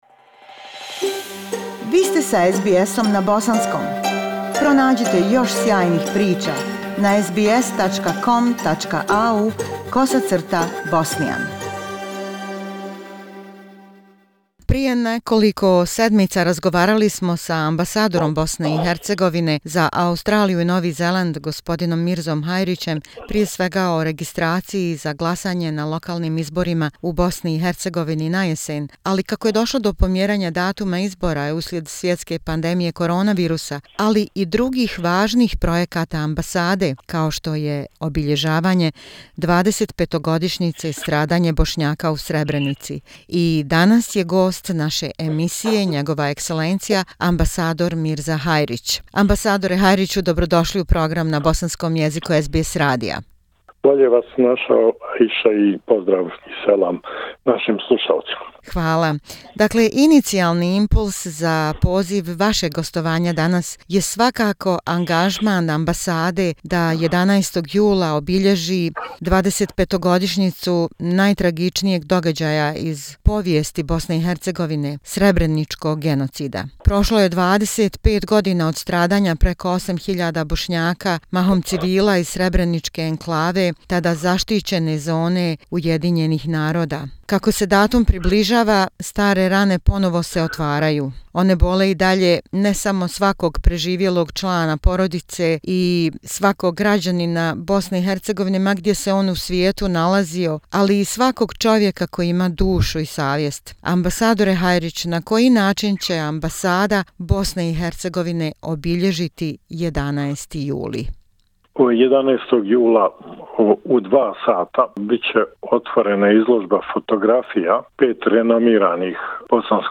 An interview with ambassador of Bosnia and Herzegovina, H.E. Mirza Hajric: Meeting 11th of July- Day of Srebrenica remembrance